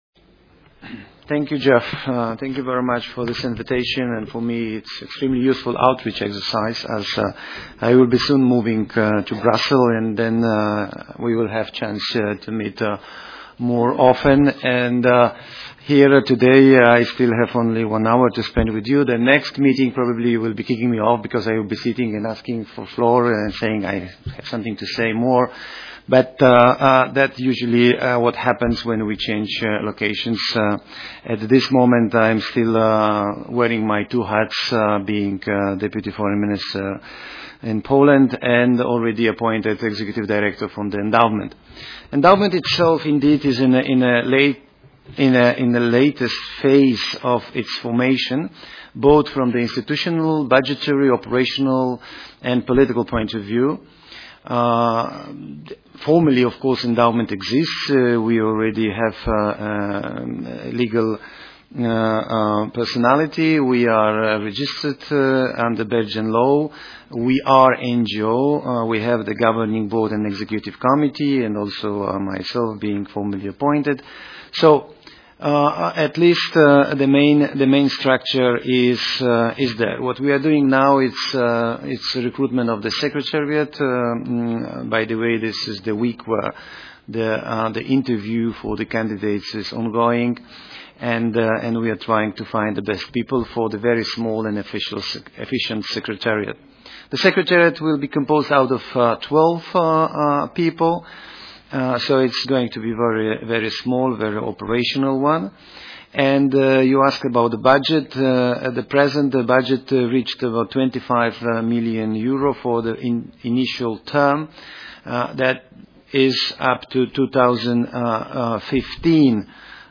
Annual Meeting of Working Group 1 on 14 May in Brussels
European Endowment for Democracy - speaker Jerzy Pomianowski_Small.mp3